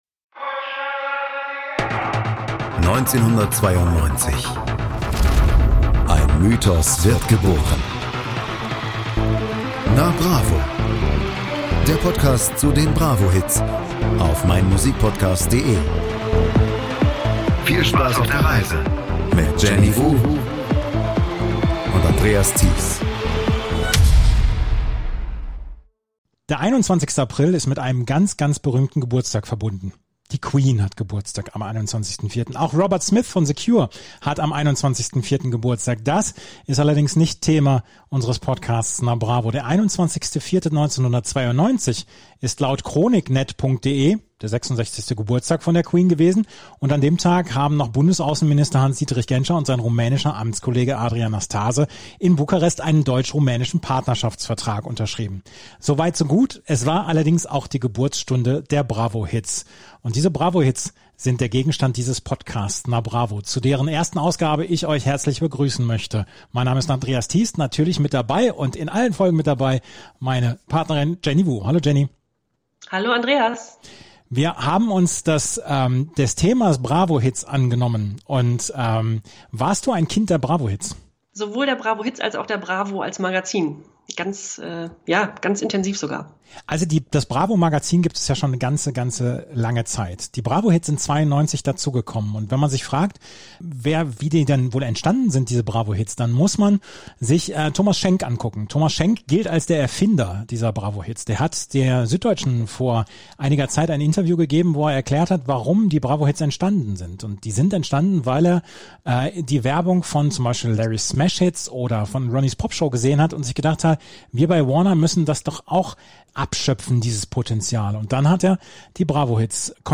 Und um euch mit so manchem Ohrwurm zu entlassen, gibt es natürlich auch den einen oder anderen Soundschnipsel.